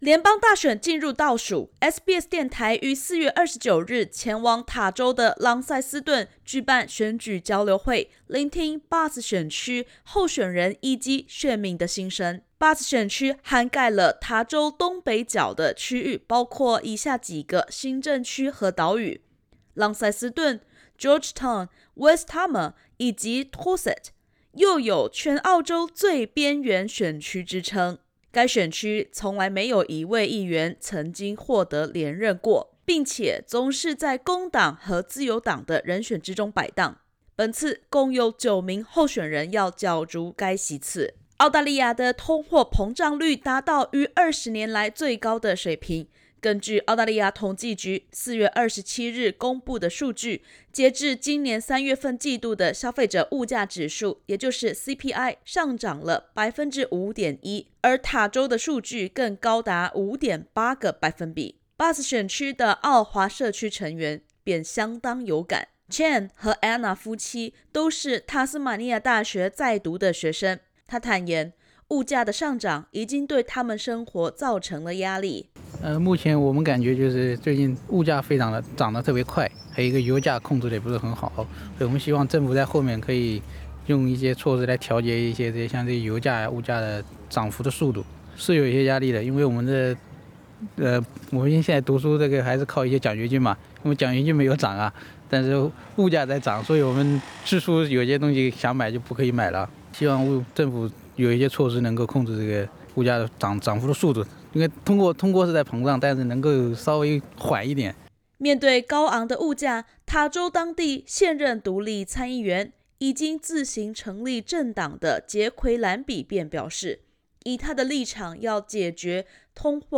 联邦大选将至，SBS电台于4月29日前往塔州朗塞斯顿，举办选举交流会，聆听Bass选区候选人与选民的心声。 （点击首图收听采访音频）